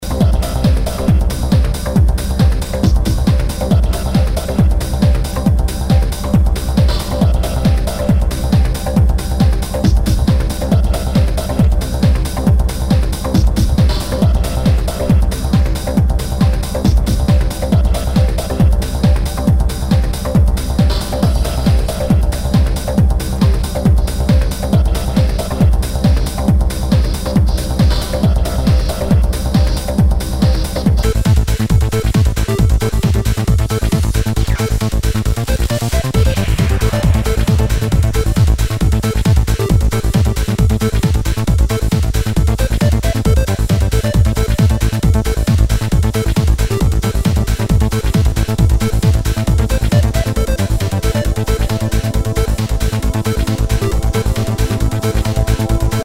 HOUSE/TECHNO/ELECTRO
ナイス！プログレッシブ・トランス！
類別 Trance